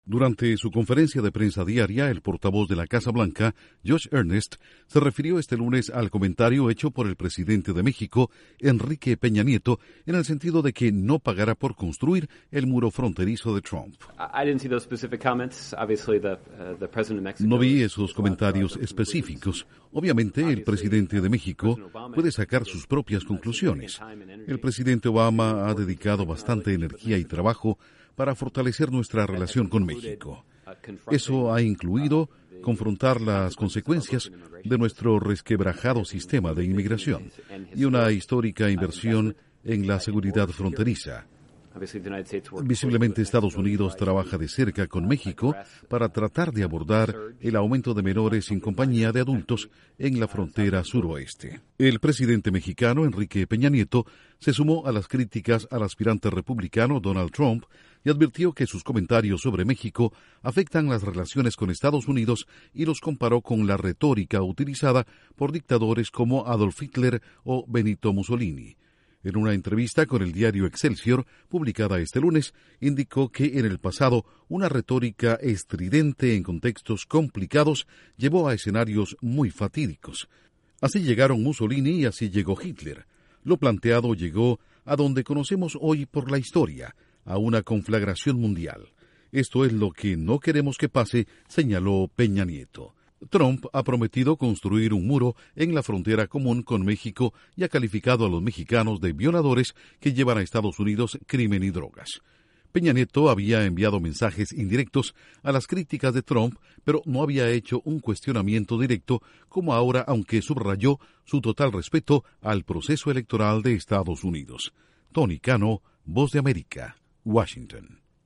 Intro: La Casa Blanca reacciona a comentario del presidente de México que aclara que no pagará por el muro fronterizo de Trump. Informa desde la Voz de América